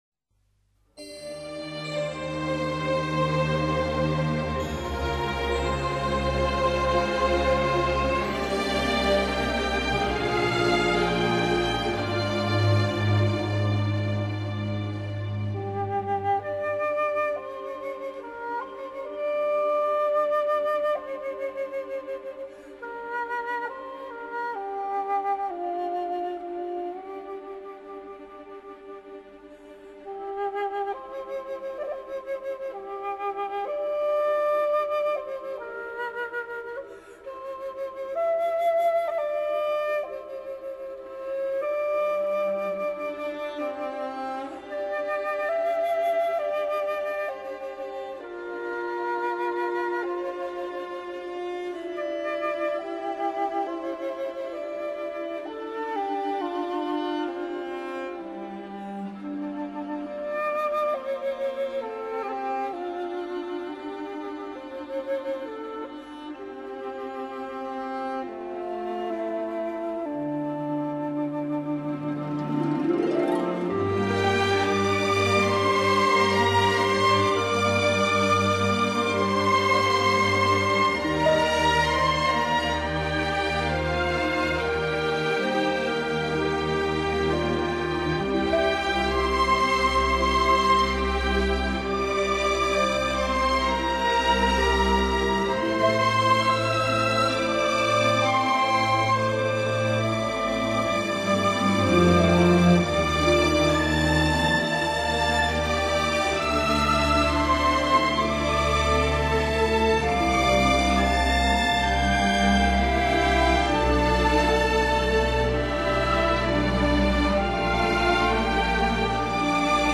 ②专辑类别：原声大碟
琴独奏，二者不久又归于统一。接着就是小提琴合唱，笛子滞后独奏。再经一次合为一之后，三弦一击便摇摆不定，余音缭绕。
曲子变化多端，但是主调始终不变，在缓慢的提琴与笛子声中，我们很容易被其中不然而然的悲伤所动容。